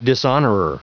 Prononciation du mot dishonorer en anglais (fichier audio)
Prononciation du mot : dishonorer